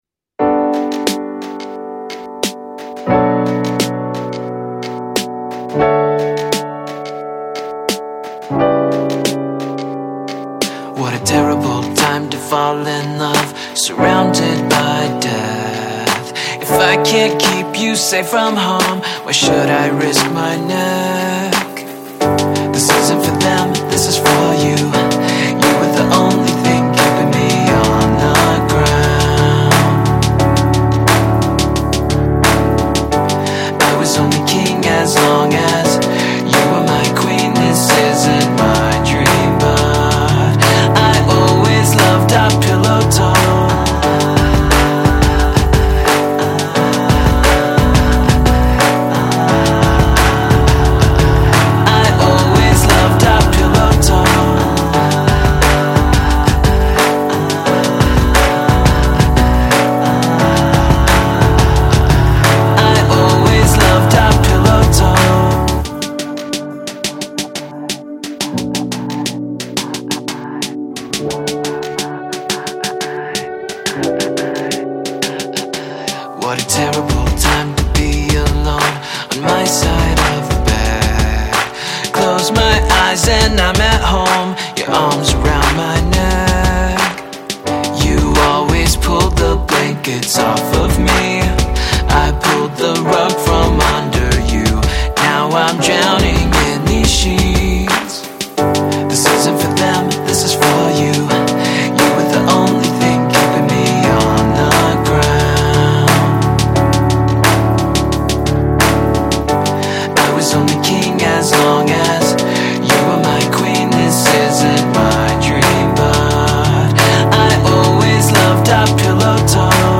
American electropop duo
vocalist